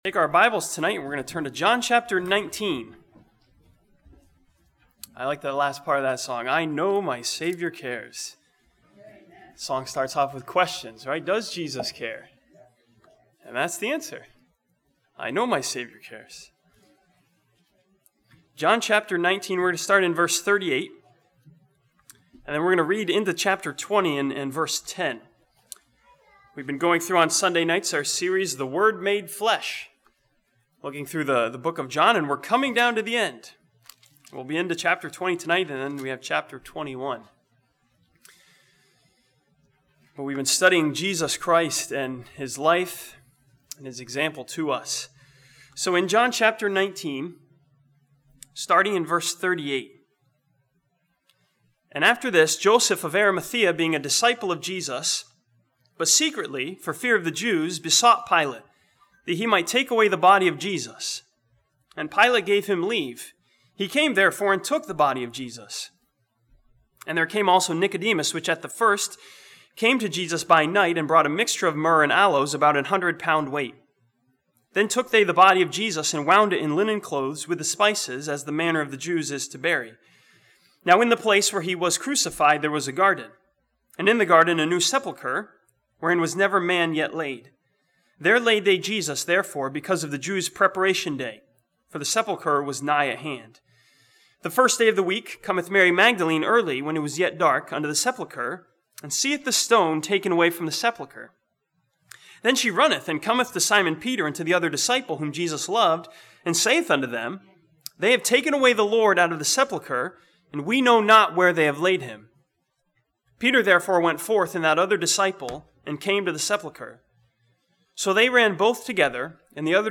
This sermon from John chapter 19 studies the time between the crucifixion and resurrection and learns what we should do when life gets dark.